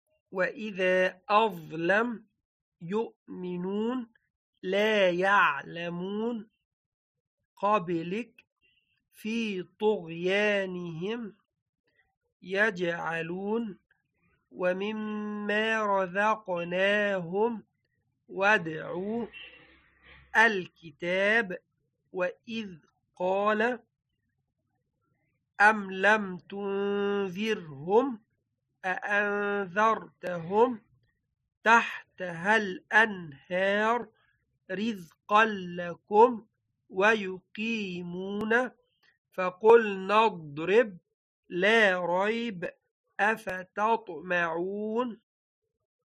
• Definition: the stoppage of breath upon pronunciation of a letter when it carries a SUKOON.
• This is achieved by stopping the air flow at the makhraj of the letter.